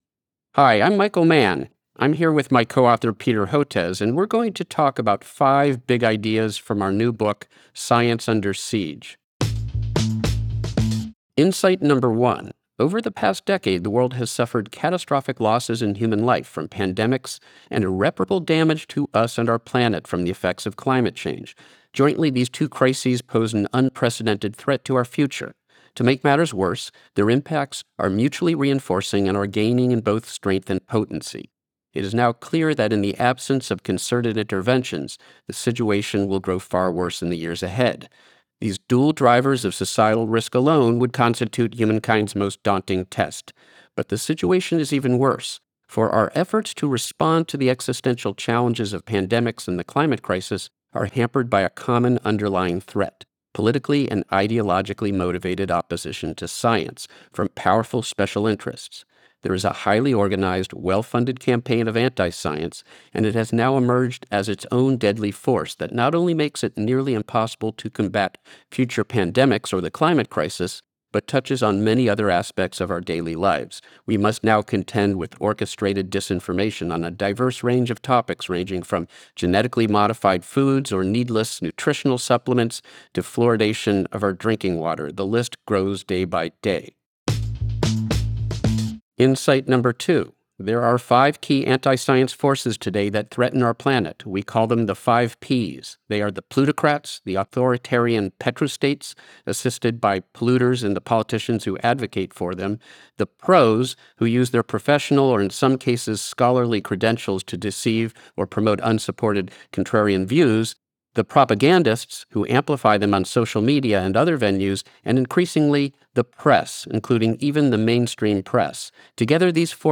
Listen to the audio version of this Book Bite—read by Michael and Peter—below, or in the Next Big Idea App.